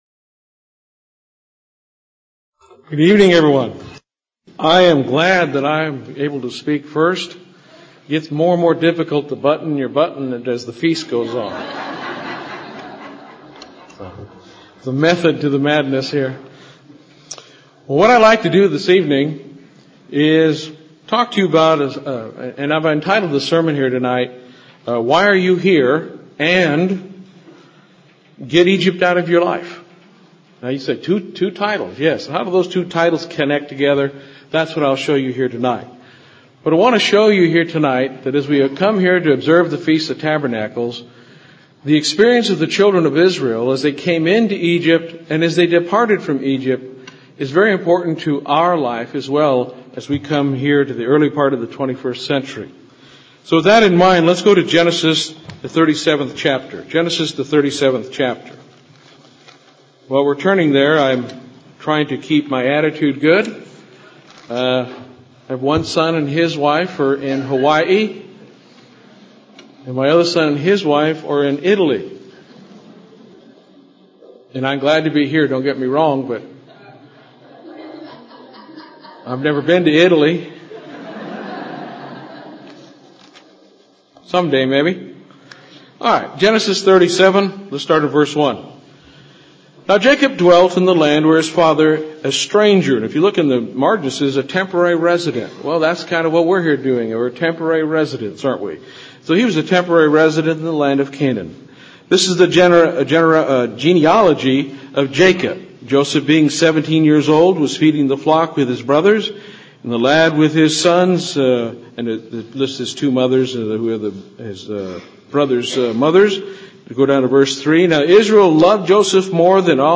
This sermon was given at the Kerrville, Texas 2009 Feast site.